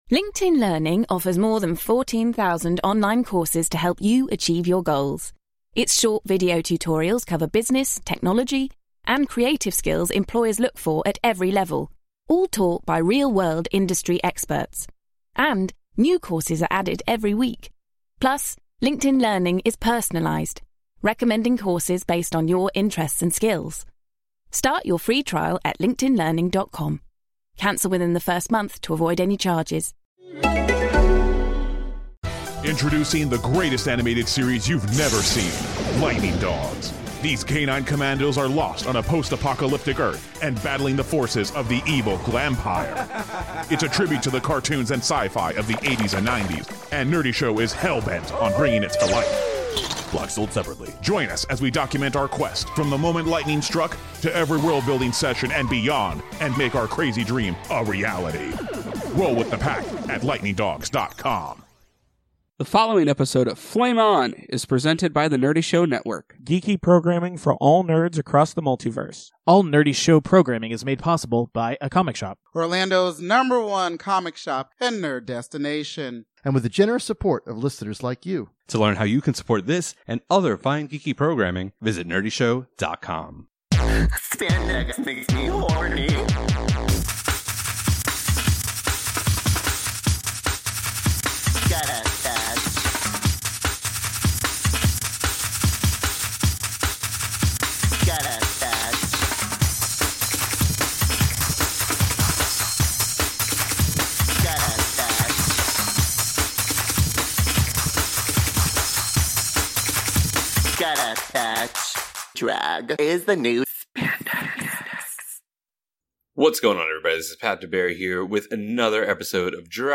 RuPaul's DragCon NYC 2019 was the gift that just keeps on giving!